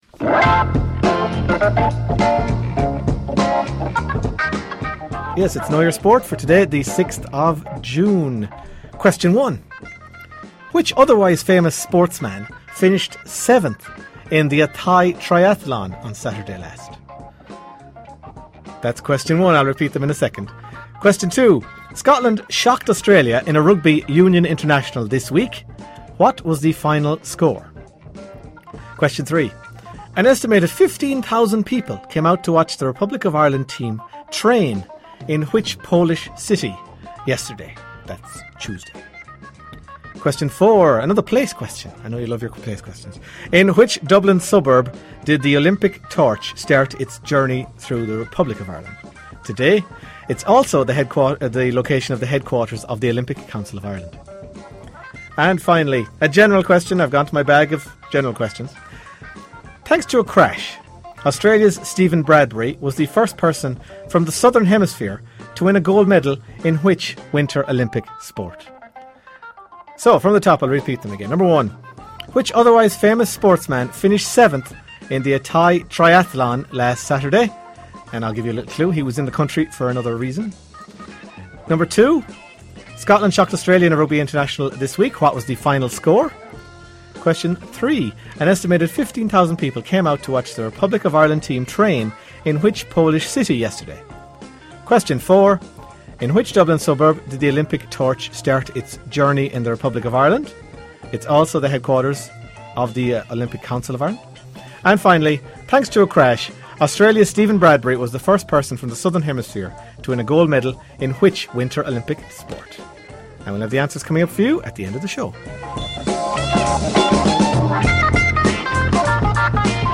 Five sports trivia questions from the Half-time Team Talk show on Claremorris Community Radio.